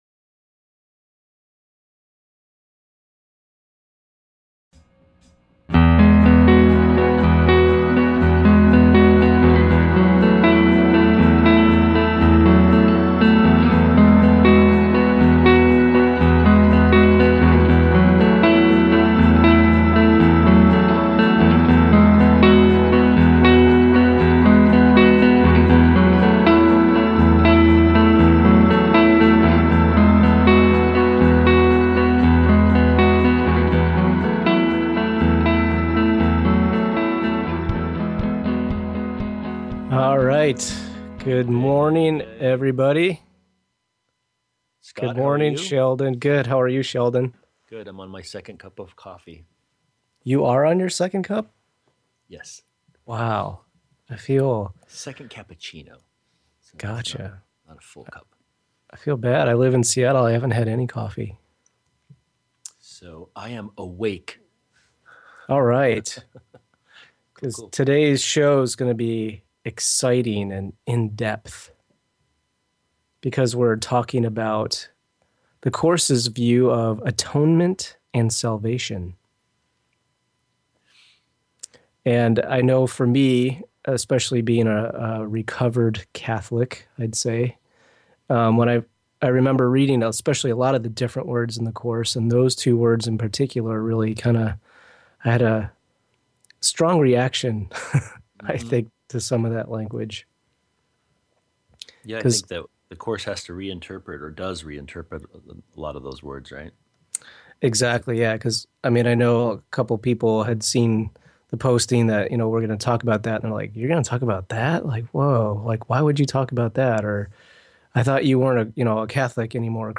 Talk Show Episode
Of Course Radio is a lively broadcast focused on the messages within ‘A Course in Miracles’ as well as in-depth explorations into how we live forgiveness in our daily lives and remember our connection with God/Source.